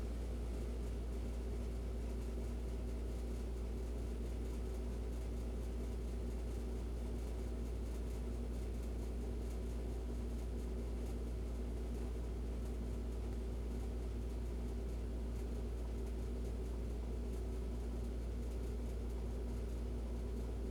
walkInFreezer_1.wav